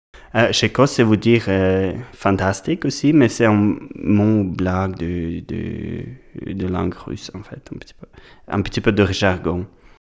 Chicos play all stop prononciation Chicos ↘ explication Chicos, ça veut dire fantastique aussi mais c’est un mot… une blague de langue russe.